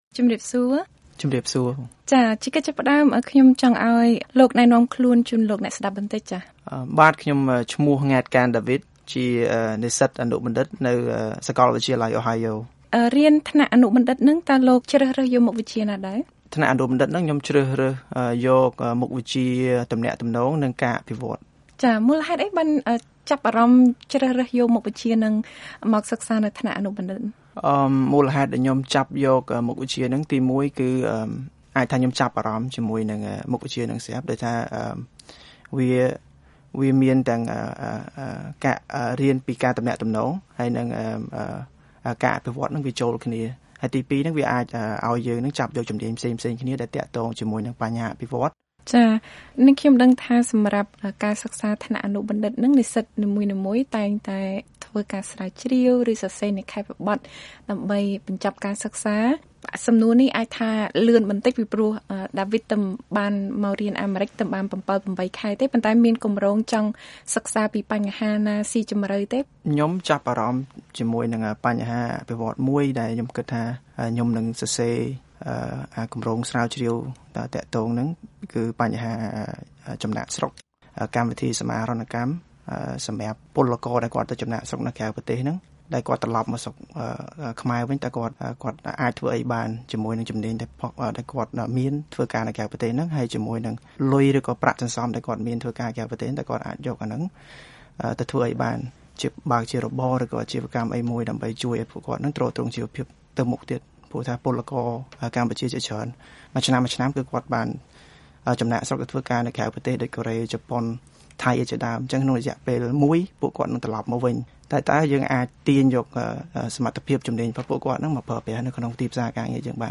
បទសម្ភាសន៍ VOA